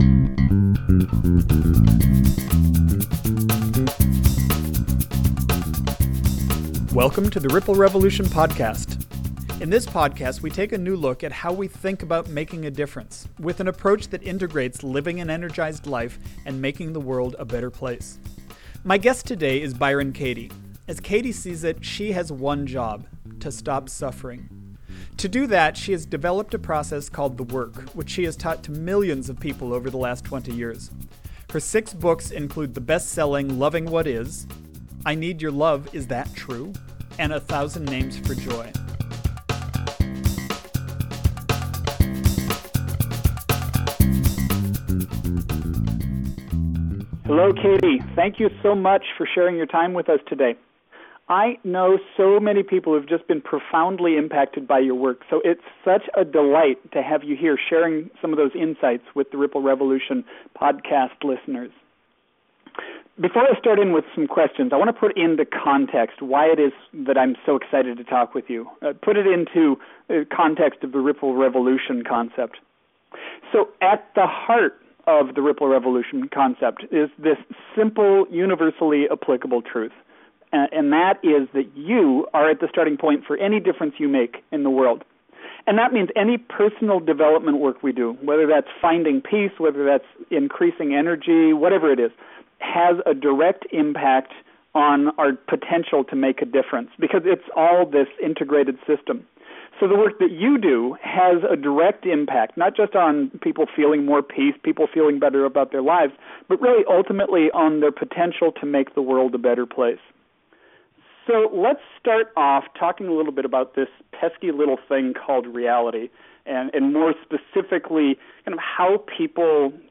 So I was completely psyched to have an opportunity to interview her as one of the first conversations in The Ripple Revolution Podcast.